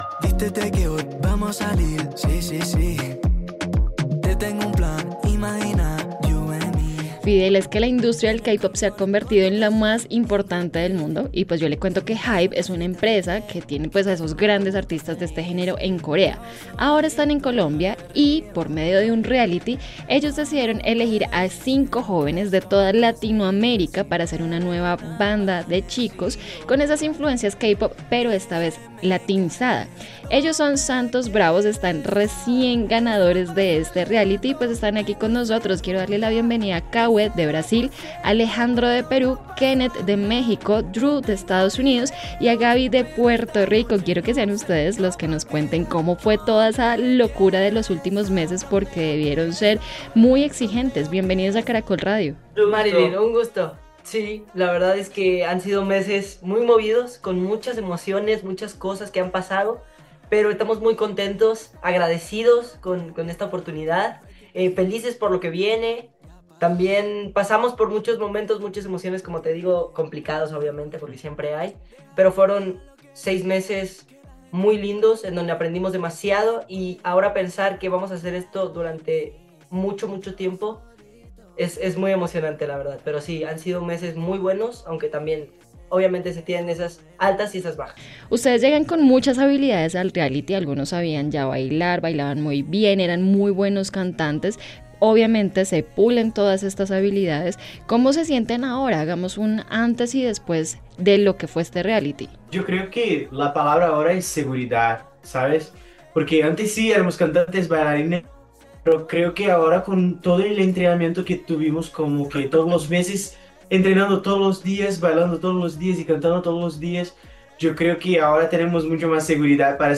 En entrevista con Caracol Radio